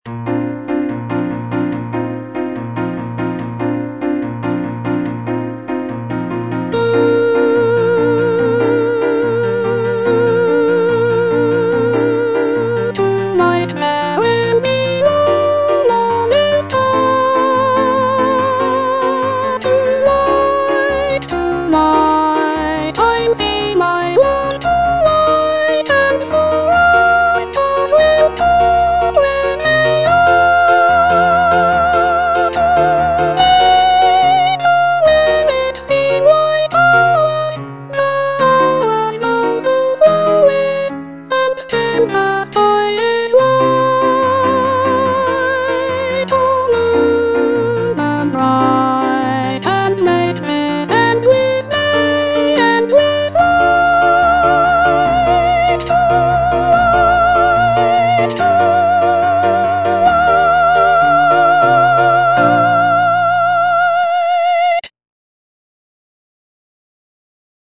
Soprano (Voice with metronome) Ads stop